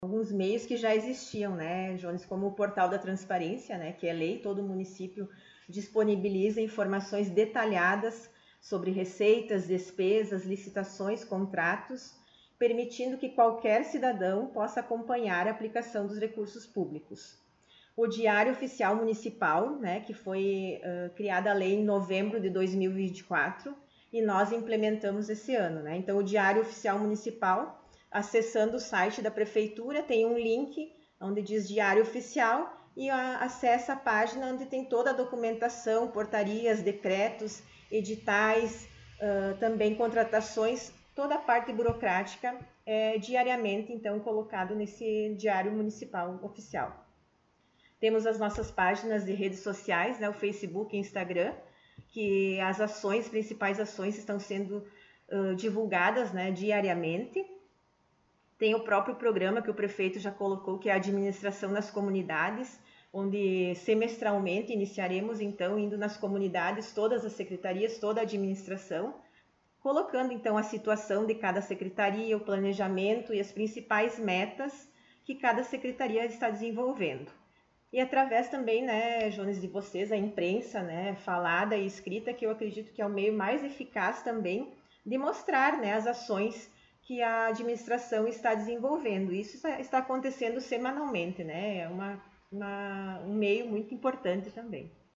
Secretária Municipal de Administração e Fazenda concedeu entrevista
O Colorado em Foco esteve na Prefeitura, na sala da secretária, para sabermos um pouco mais da situação econômica do município e outros assuntos.